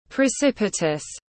Cao và dốc tiếng anh gọi là precipitous, phiên âm tiếng anh đọc là /prɪˈsɪp.ɪ.təs/ .
Precipitous /prɪˈsɪp.ɪ.təs/